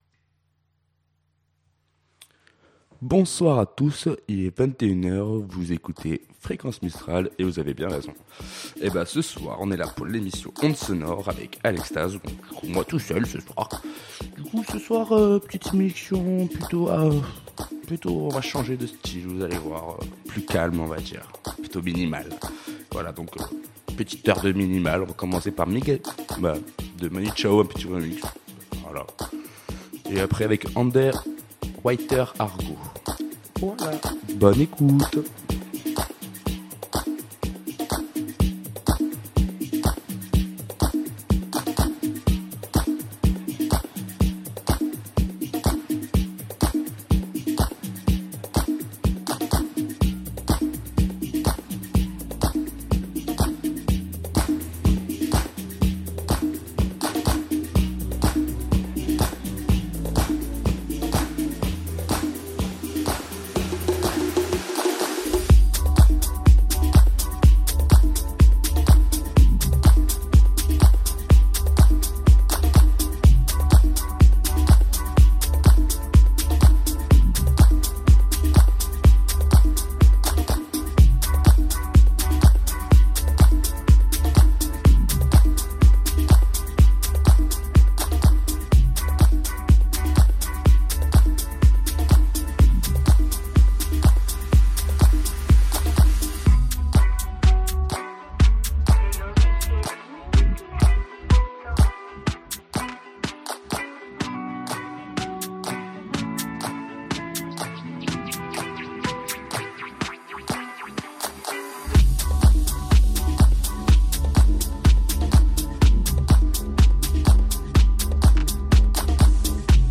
Emission électro-House-techno